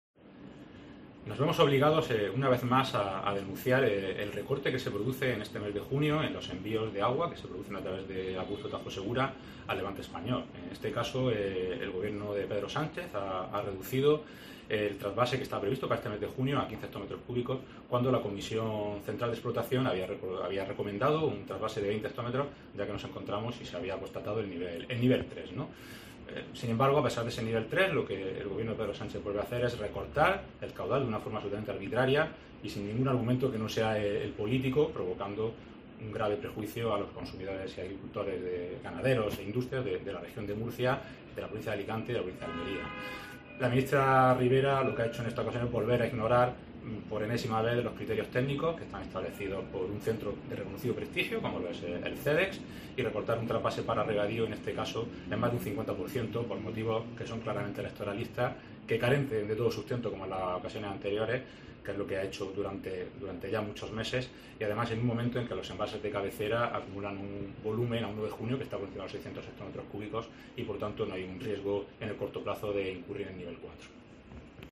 José Sandoval, director general del Agua